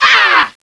spider_hurt2.wav